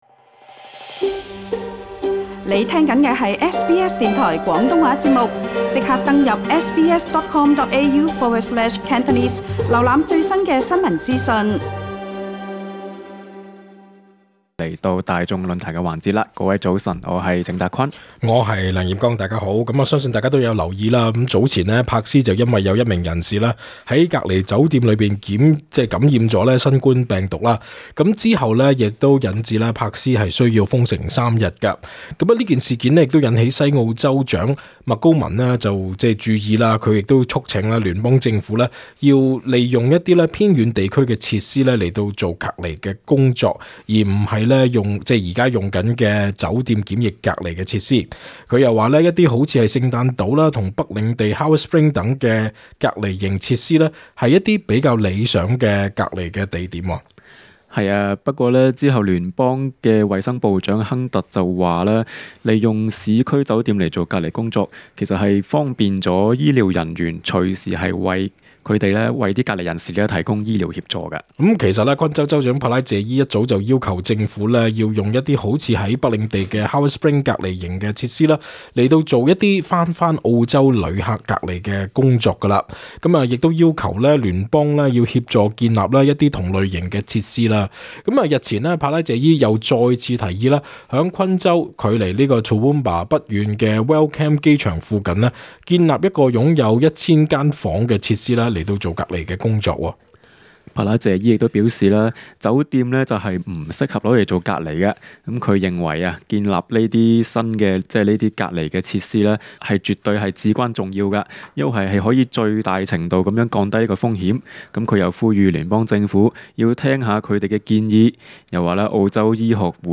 本節目內嘉賓及聽眾意見並不代表本台立場 READ MORE 澳洲大型隔離營擴充 數萬滯留海外人士將加快回國 READ MORE 【本台聽眾贊成建獨立檢疫設施】維州冀聯邦撥款建造 【注意！